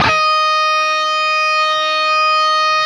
LEAD D#4 CUT.wav